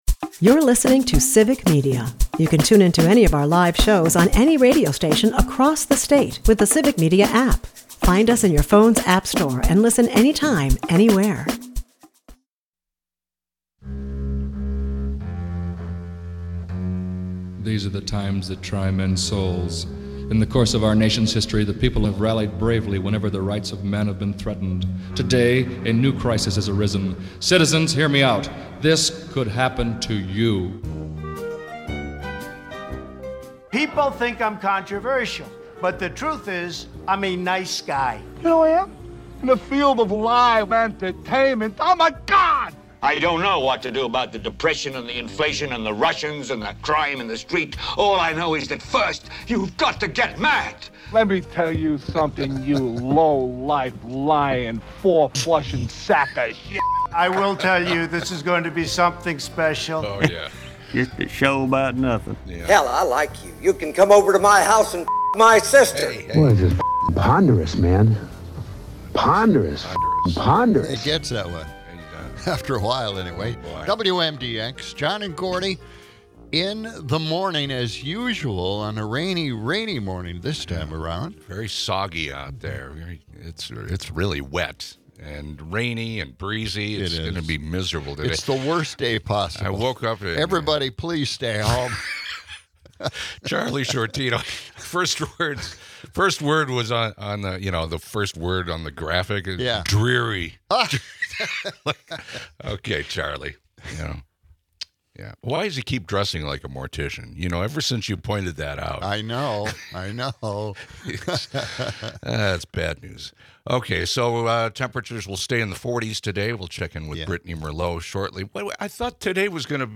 They also discuss the overhaul of Social Security and its digital exclusion of seniors. Amid all this, a banana bread bake-off stirs chaos in the studio, with confusion over the scheduled competition and leftover woes. Plus, a rainy weather update and witty banter round out the episode.